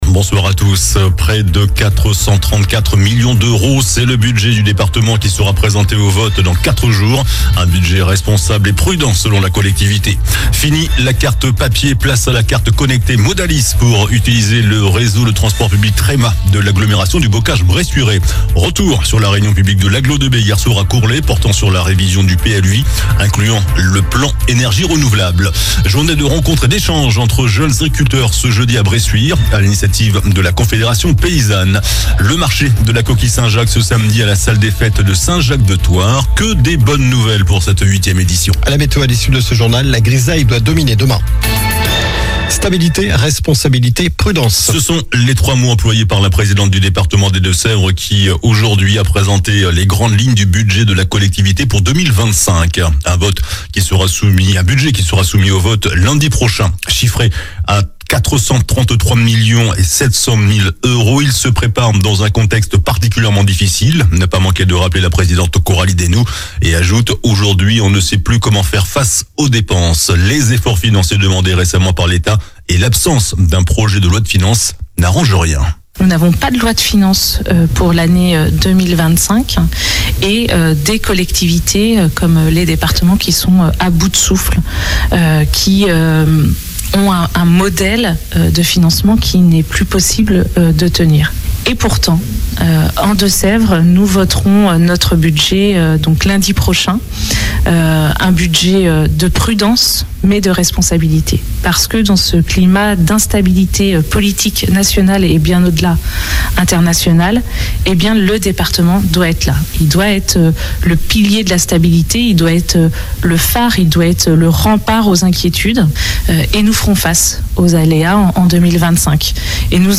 JOURNAL DU JEUDI 12 DECEMBRE ( SOIR )